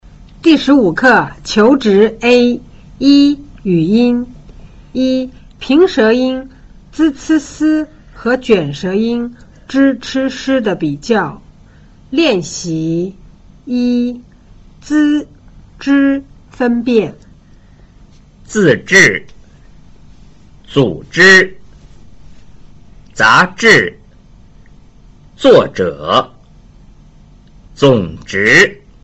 1. 平舌音 z c s和 捲舌音 zh ch sh 的比較﹕